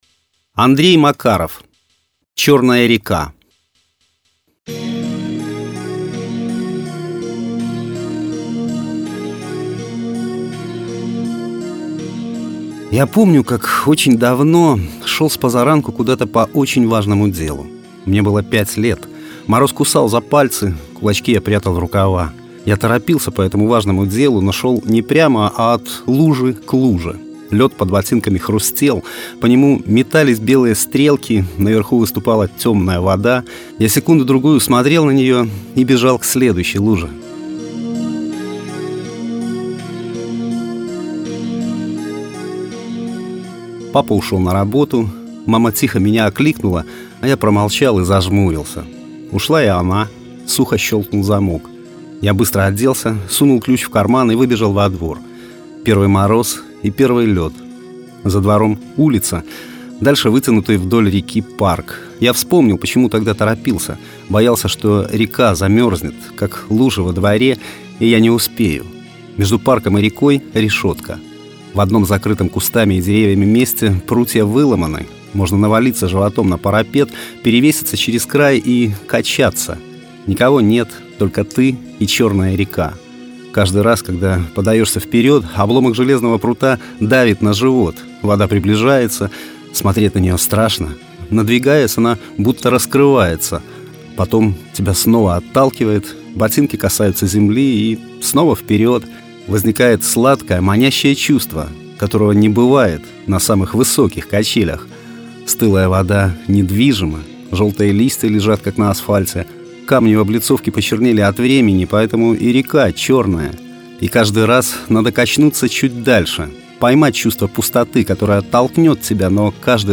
Аудиорассказ
Жанр: Современная короткая проза
Качество: mp3, 256 kbps, 44100 kHz, Stereo